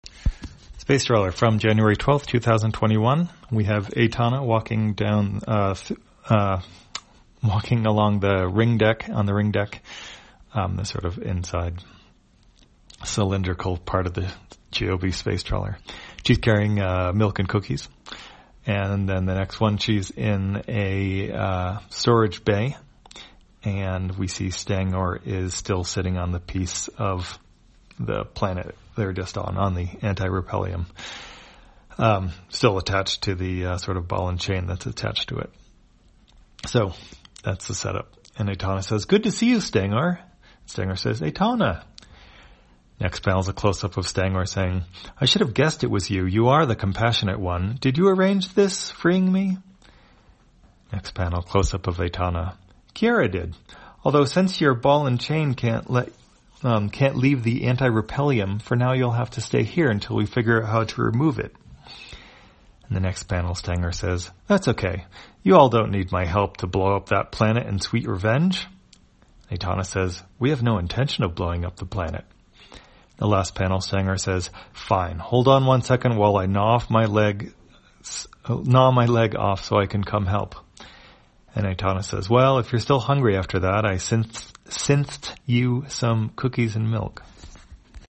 Spacetrawler, audio version For the blind or visually impaired, January 11, 2021.